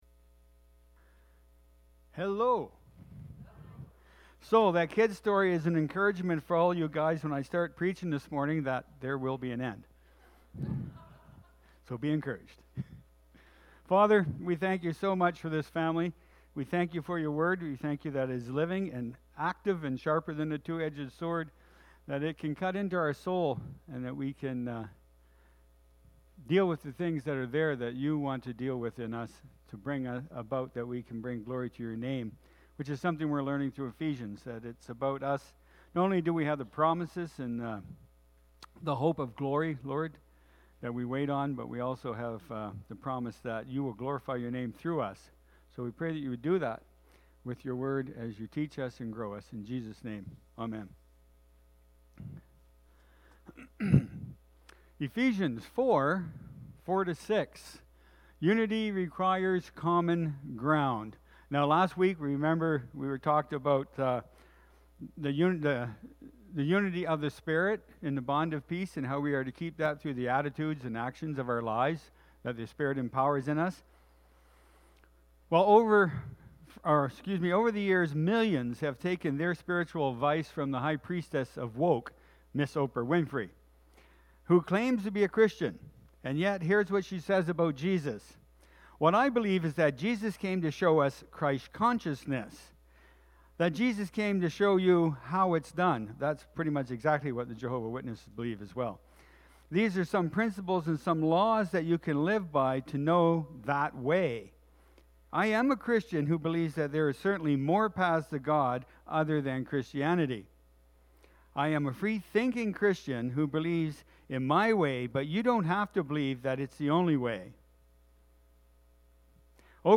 April-2-2023-Sermon-Audio.mp3